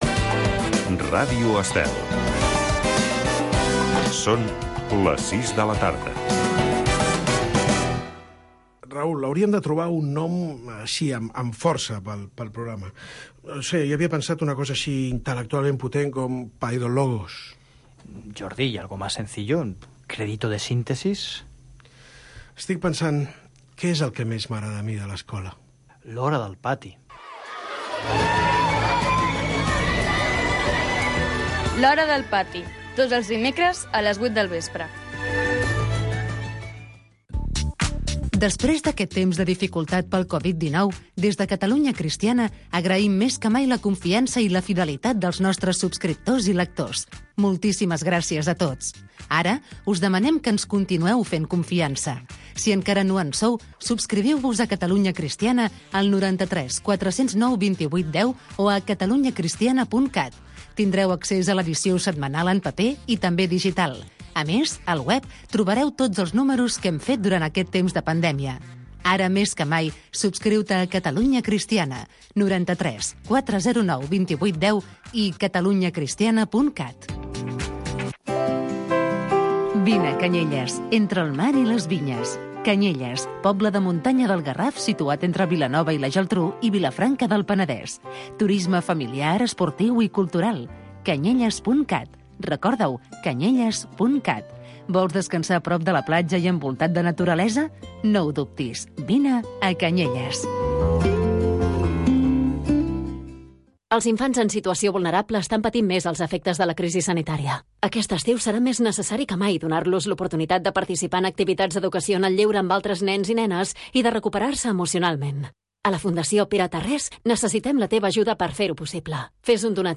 En concert. Repàs de concerts emblemàtics.